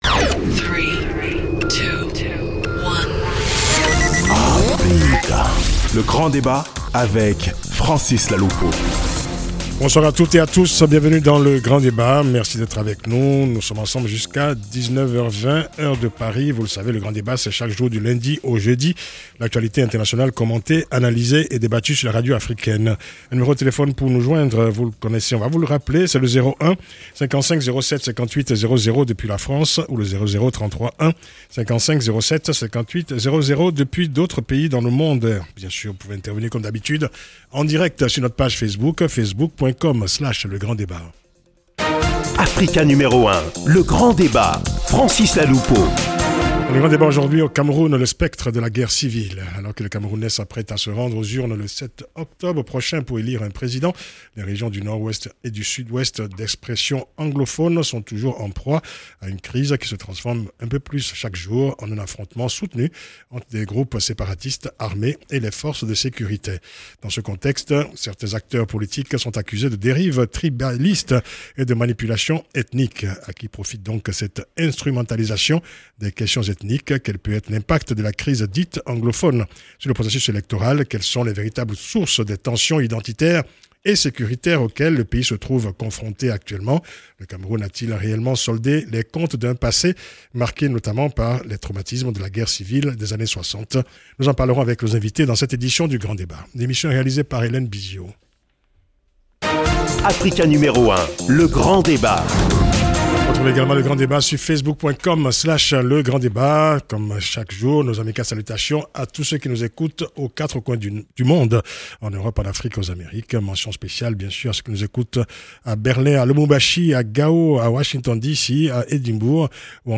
Le Grand Débat – 04/09/2018